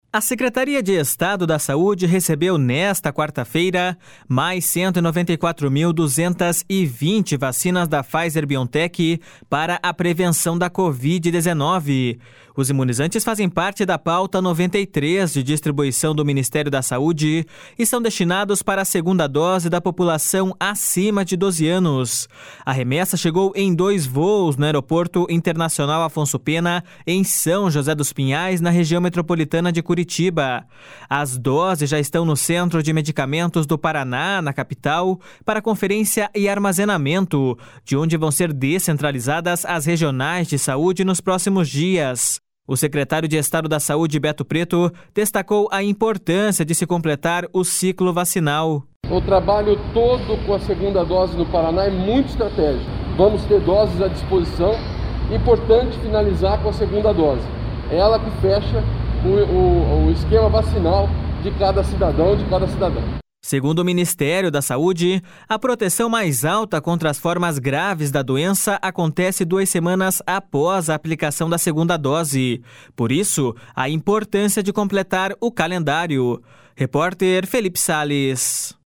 O secretário de Estado da Saúde, Beto Preto, destacou a importância de se completar o ciclo vacinal.// SONORA BETO PRETO.//